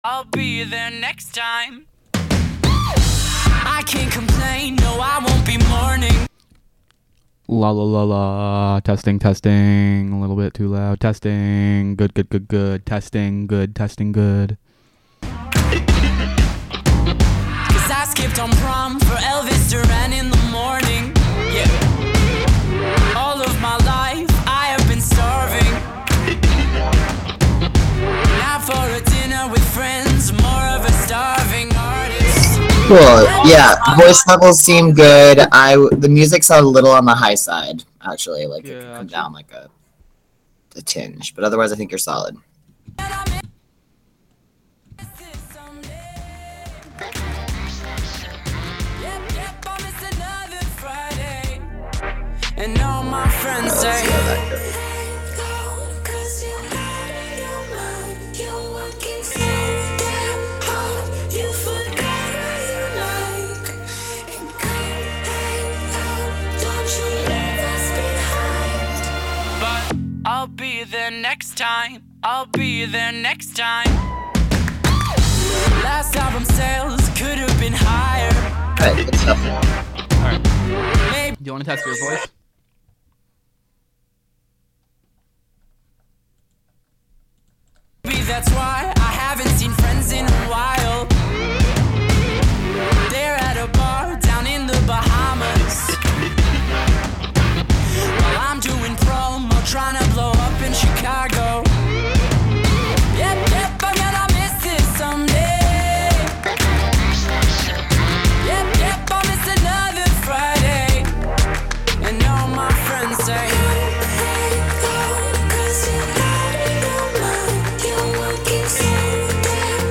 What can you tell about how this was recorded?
Live from the Catskill Clubhouse.